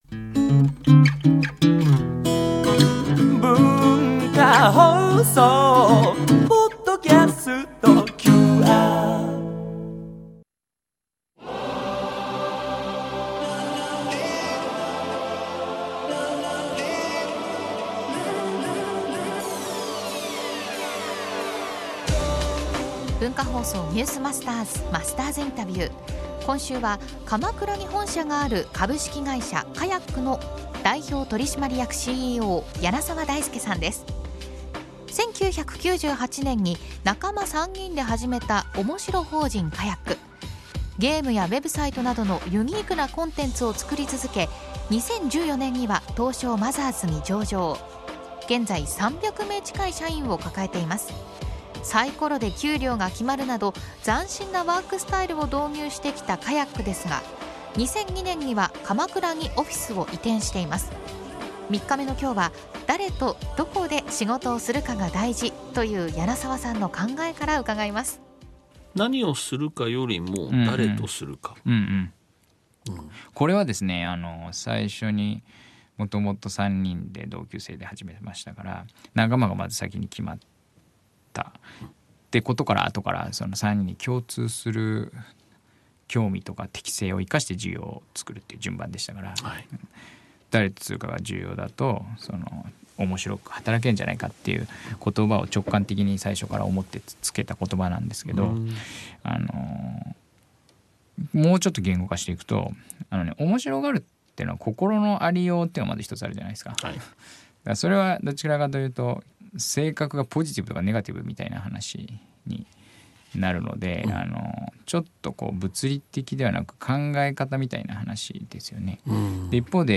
毎週、現代の日本を牽引するビジネスリーダーの方々から次世代につながる様々なエピソードを伺っているマスターズインタビュー。